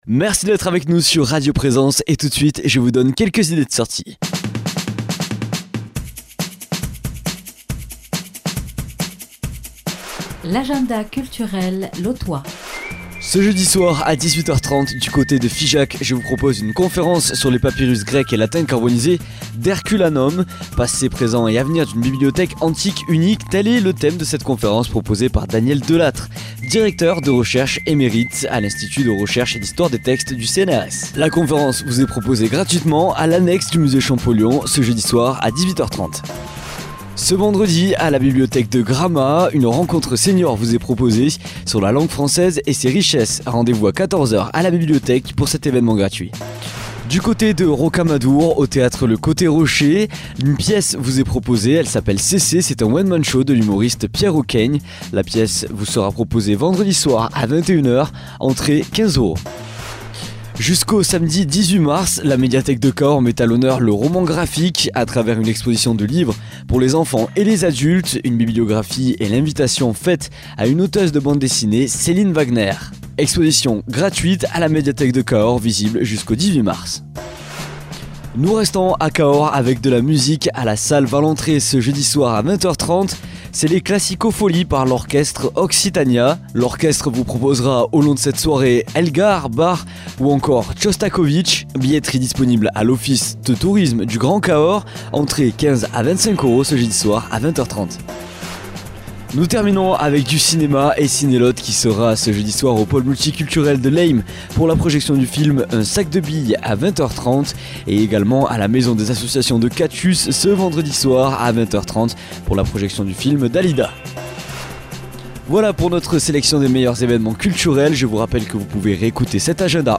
Blues
Présentateur